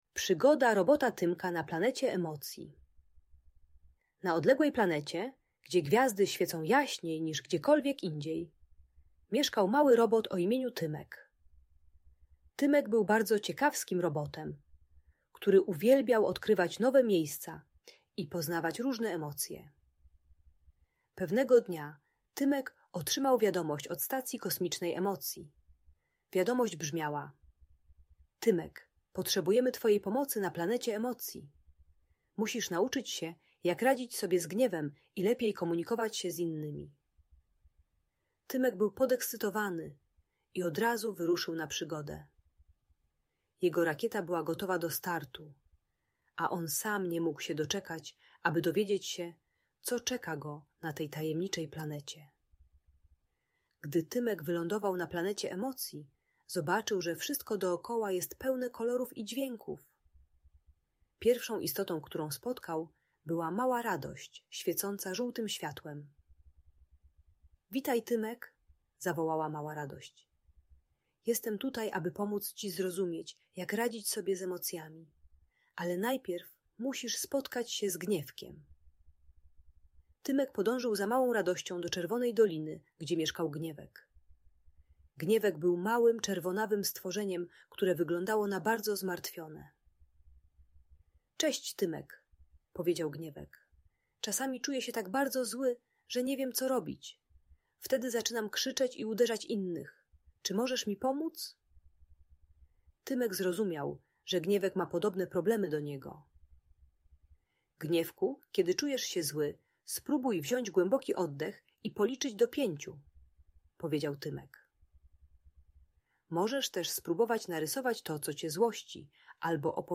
Przygoda Robota Tymka na Planecie Emocji - Audiobajka